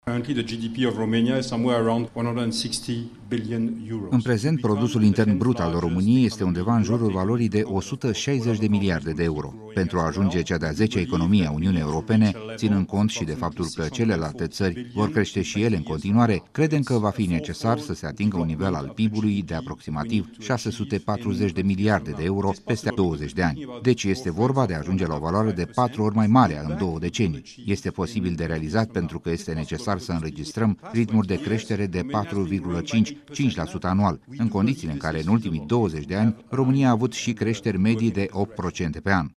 la o reuniune dedicată investitorilor organizată în Capitală de publicatia Business Review.